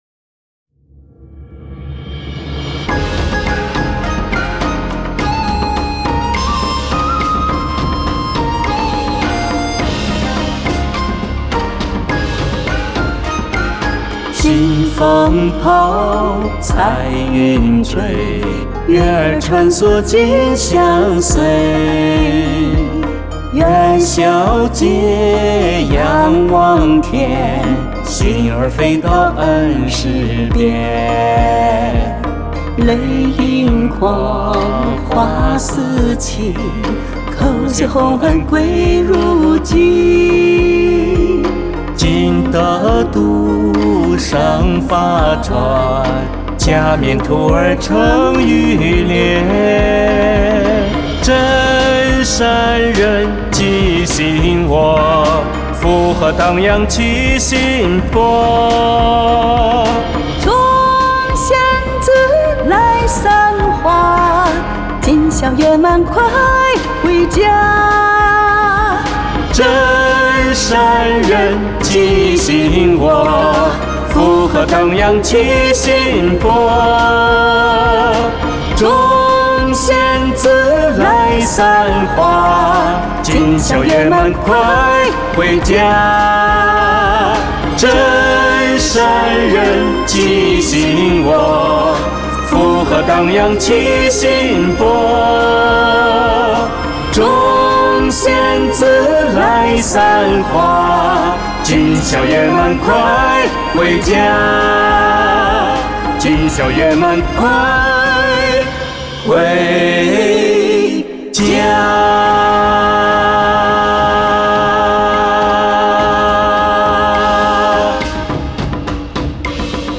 【恭祝師尊元霄節快樂】男女聲二重唱：月滿金宵 | 法輪大法正見網